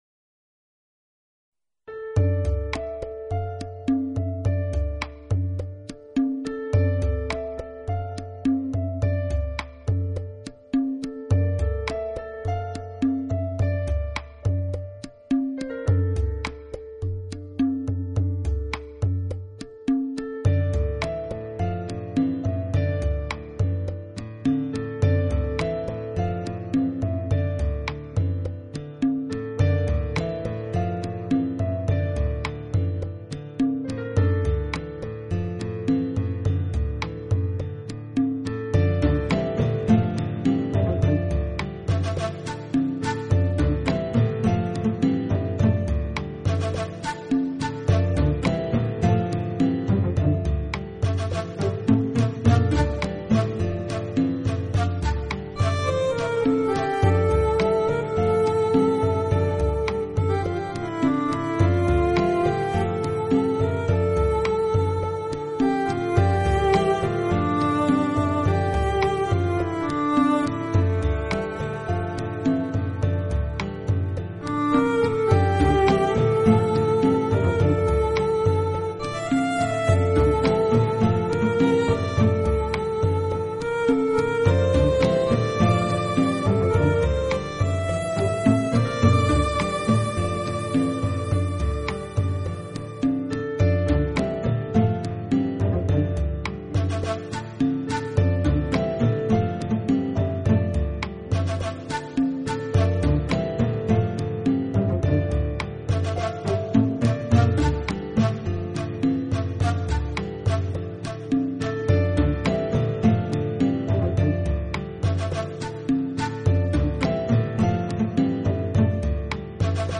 Genre..........: New Age
QUALiTY........: MP3 44,1kHz / Stereo